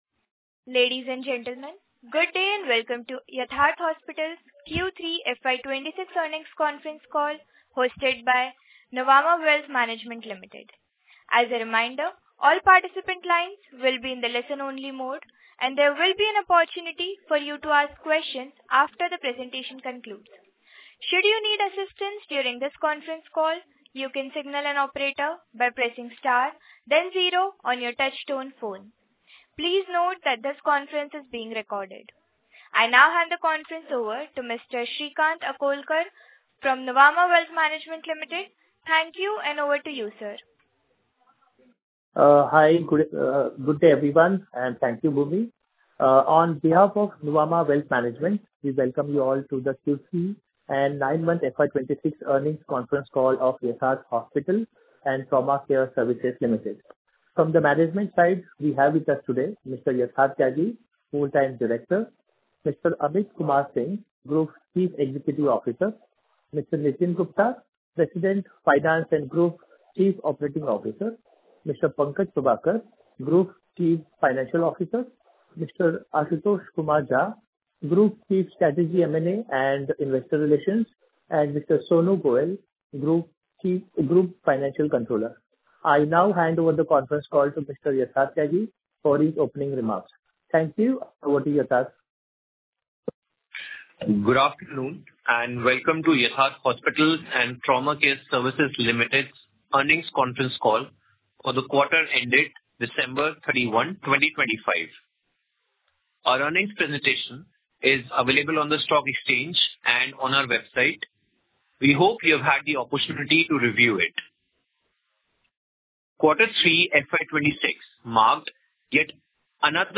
Concalls
earningscallrecordingq3fy26.mp3